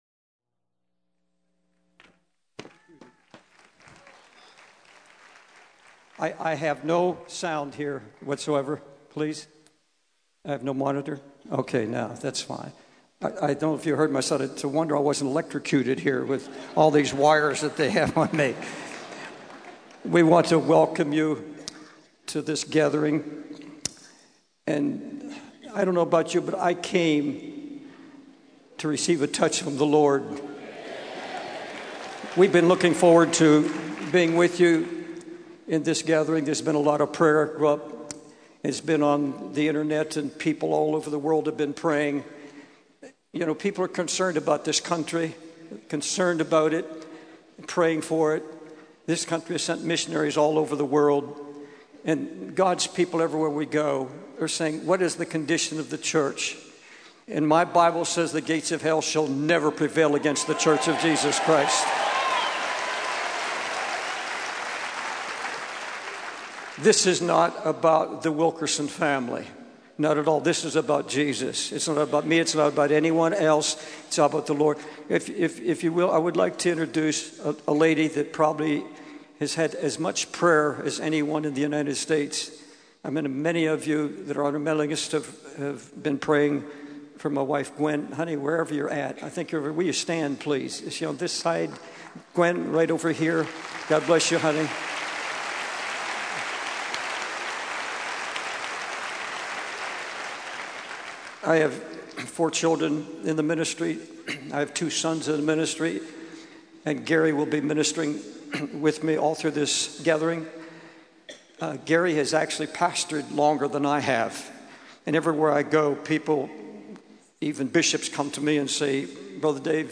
The sermon concludes with a call to stand firm in faith and not compromise the truth of the gospel.